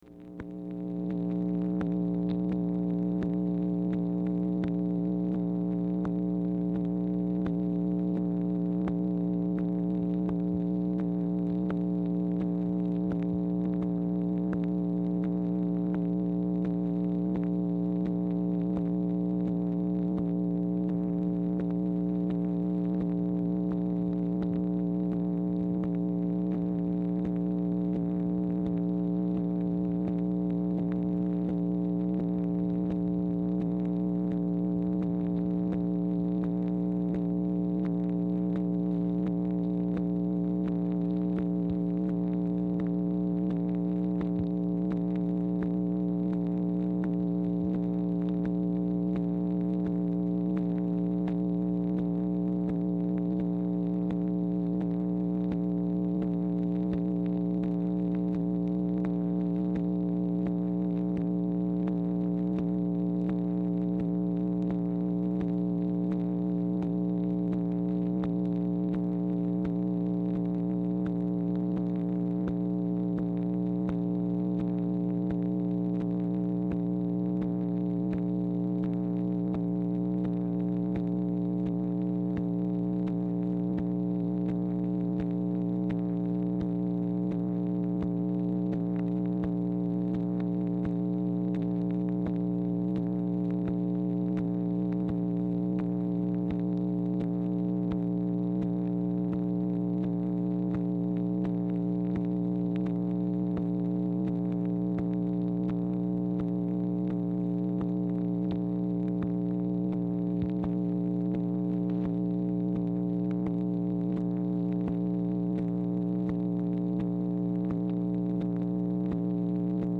Telephone conversation # 4256, sound recording, MACHINE NOISE, 7/16/1964, time unknown | Discover LBJ
Format Dictation belt
Specific Item Type Telephone conversation